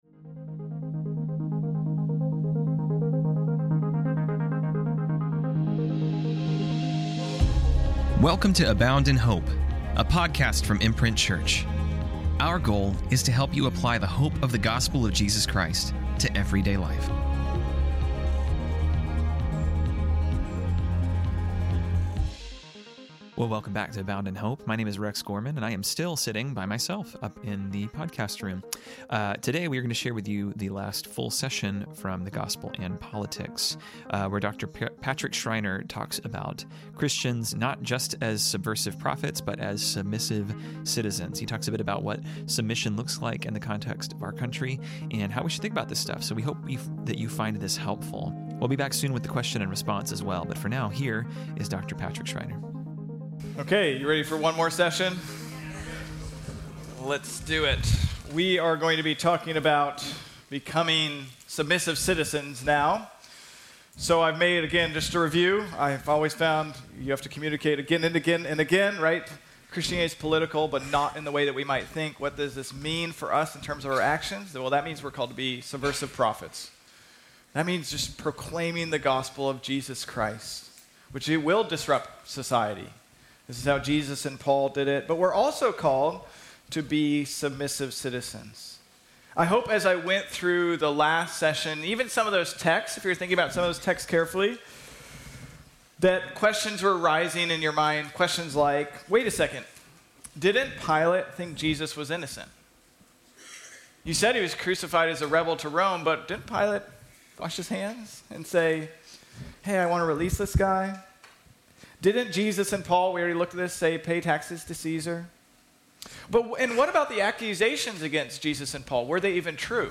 This week, we are airing the third session from The Gospel& Politics, a conference we put on in Summer of 2024.